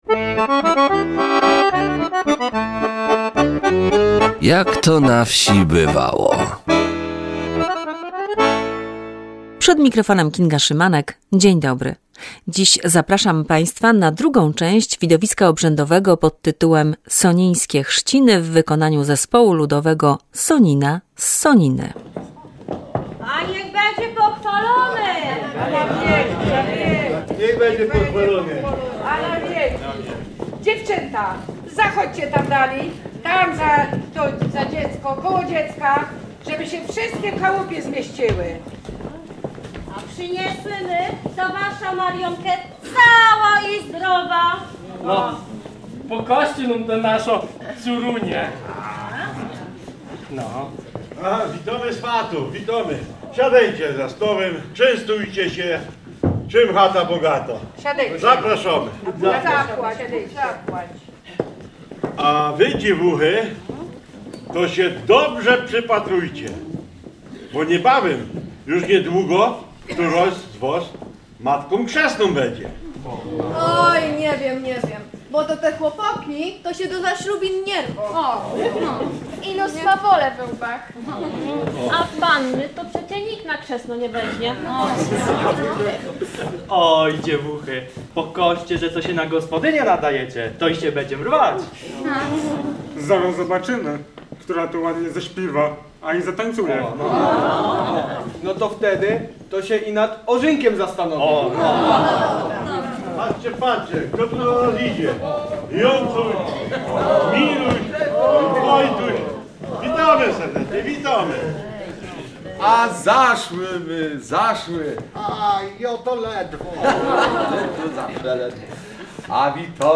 W programie część druga widowiska obrzędowego „Sonińskie chrzciny” w wykonaniu zespołu ludowego Sonina.